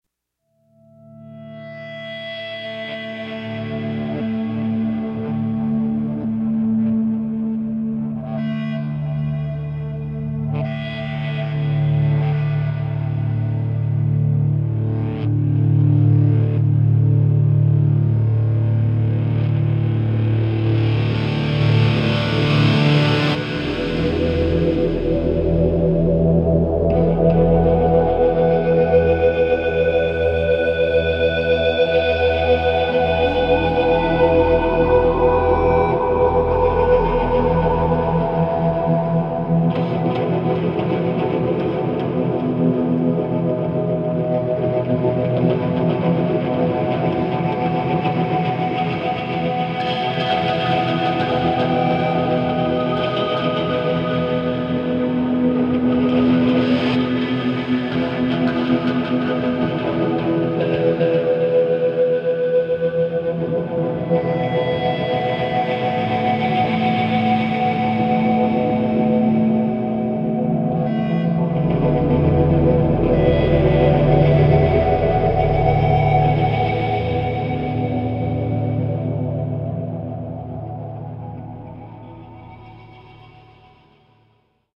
electric_guitar_ambience.ogg